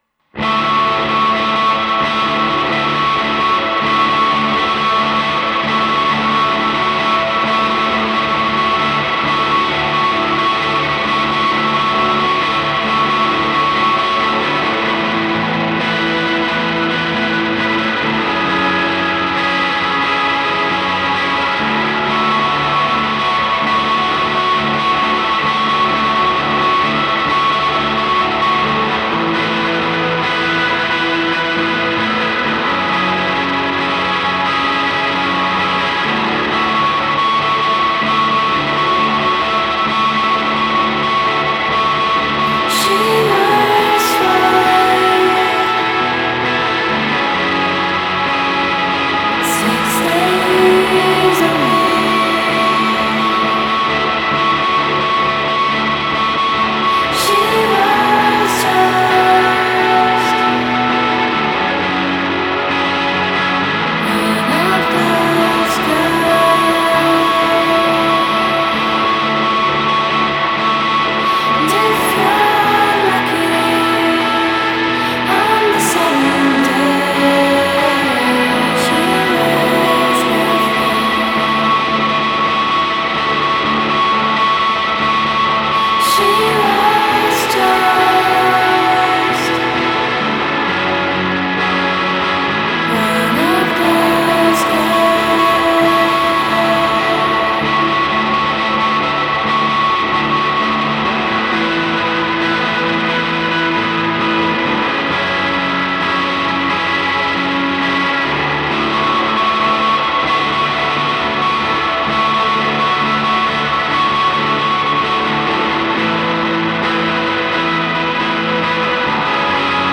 sul palco
venerdì sera